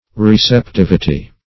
Receptivity \Rec`ep*tiv"i*ty\ (r[e^]s`[e^]p*t[i^]v"[i^]*t[y^] or
r[=e]`s[e^]p*t[i^]v"[i^]*t[y^]), n. [Cf. F.